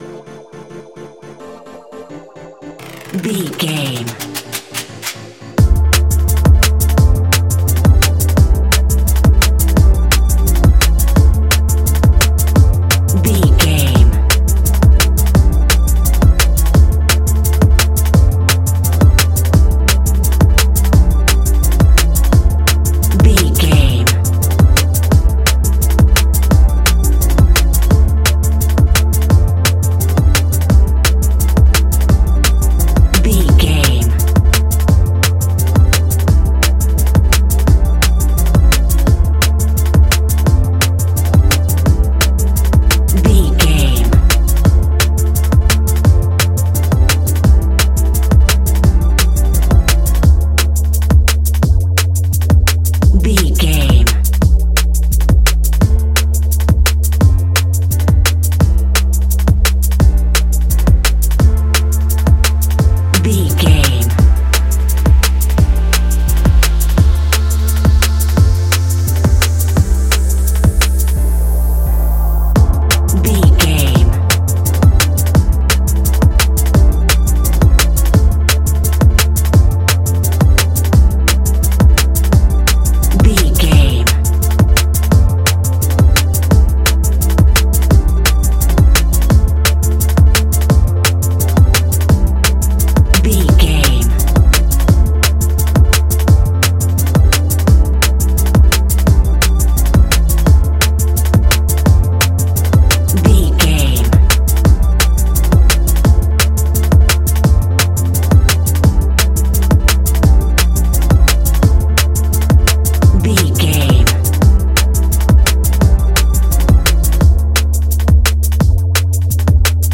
Ionian/Major
electronic
dance
techno
trance
synths
synthwave
instrumentals